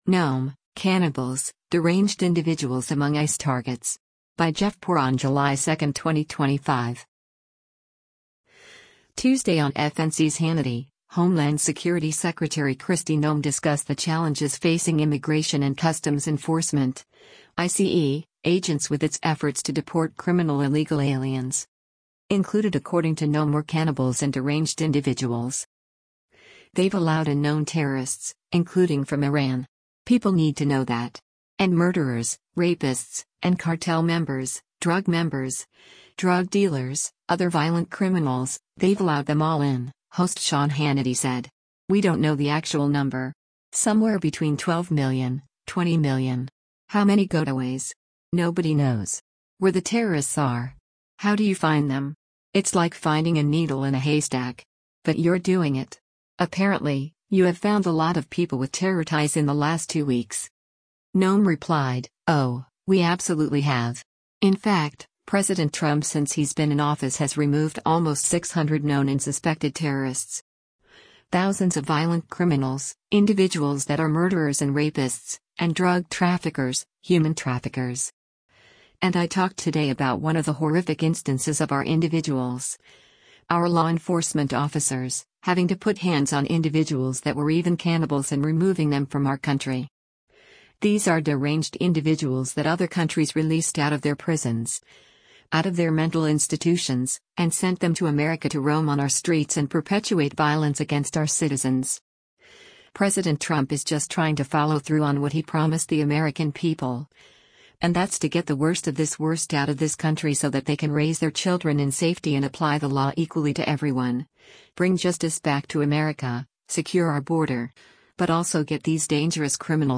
Tuesday on FNC’s “Hannity,” Homeland Security Secretary Kristi Noem discussed the challenges facing Immigration and Customs Enforcement (ICE) agents with its efforts to deport criminal illegal aliens.